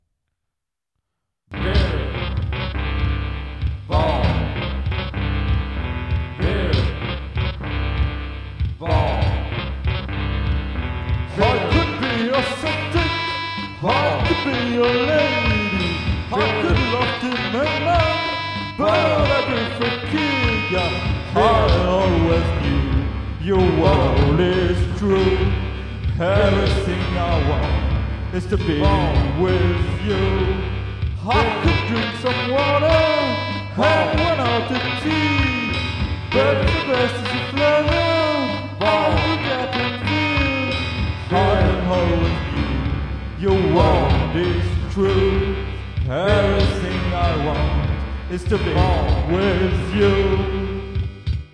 un gentil multinationalisme lo-fi
plein d entrain et de magie